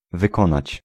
Ääntäminen
France: IPA: [a.kɔ̃.pliʁ]